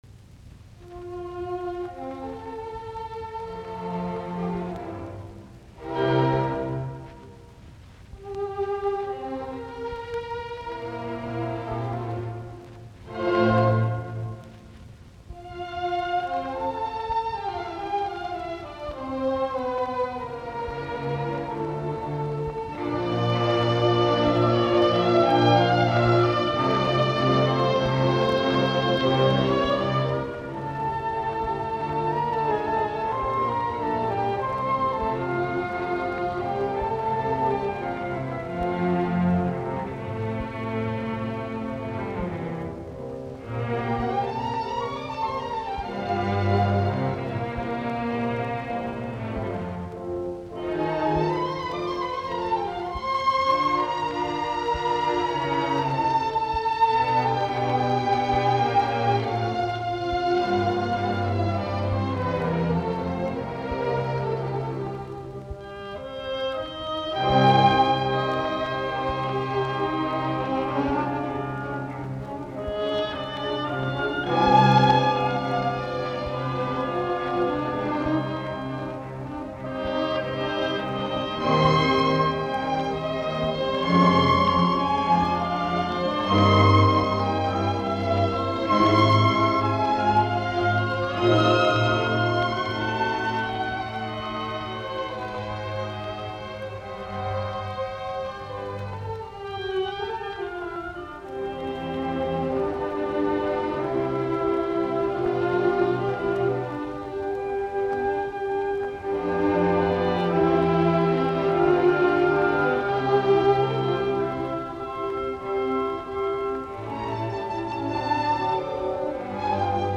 C-duuri
Allegro vivace